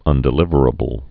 (ŭndĭ-lĭvər-ə-bəl)